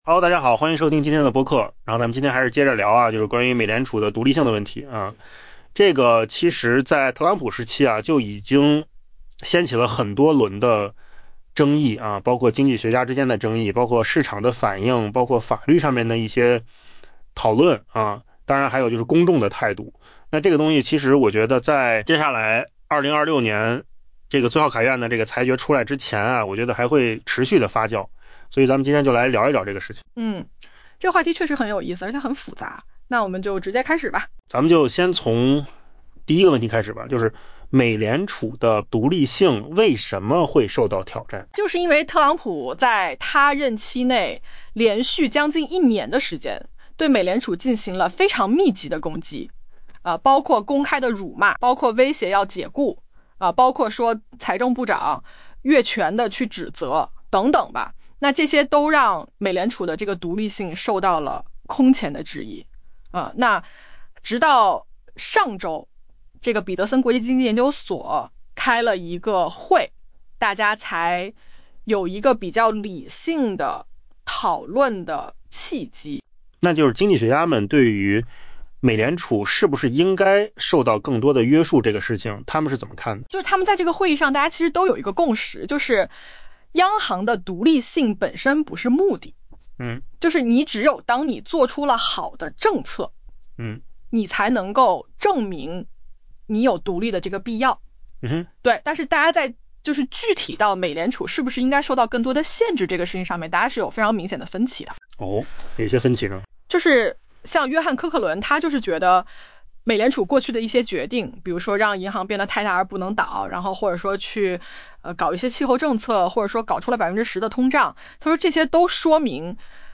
AI 播客：换个方式听新闻 下载 mp3 音频由扣子空间生成 在特朗普近一年的持续攻击下，美联储正承受重压。